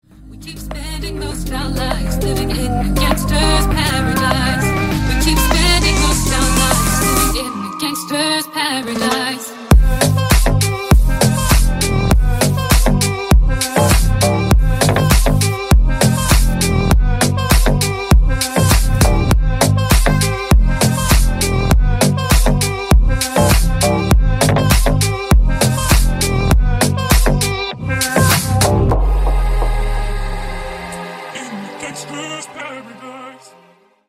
remix
Electronic
клубняк